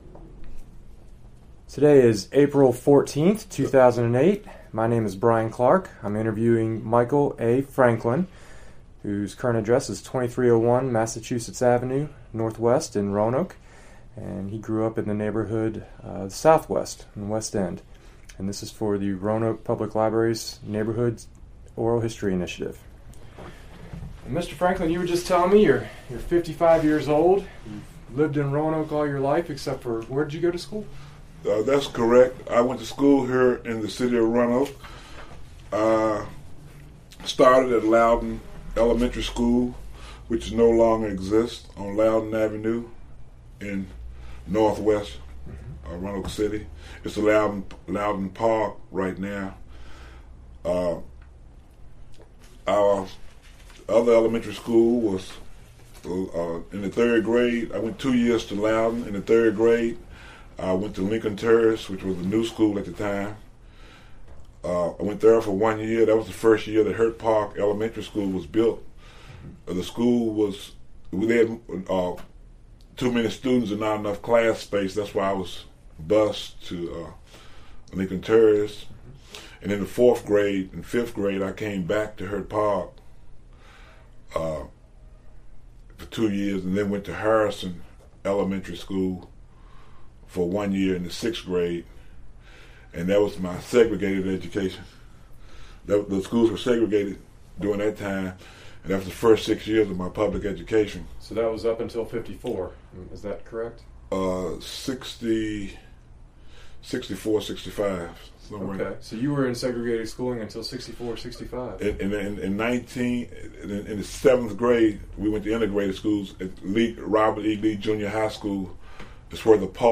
Neighborhood History Interview
Location: Mountain View Recreation Center